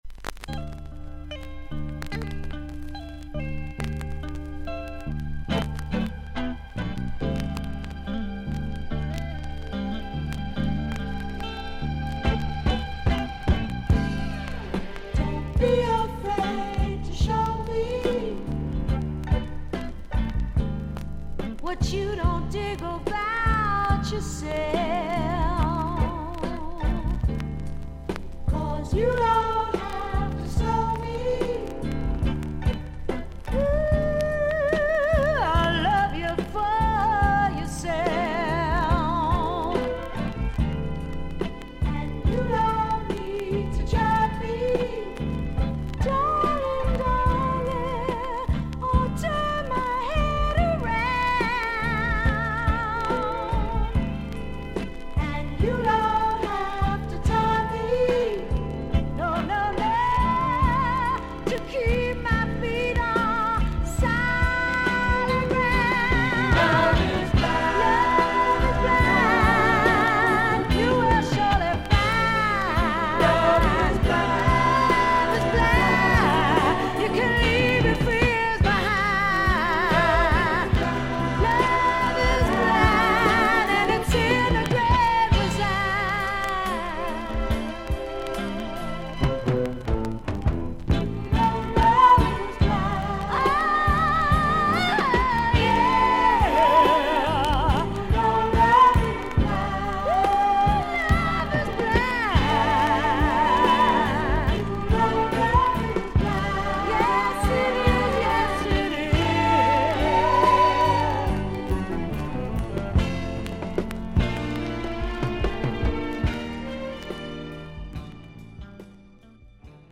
B面はVG+〜VG:長短のキズがあり、周回ノイズ、サーフィス・ノイズがあります。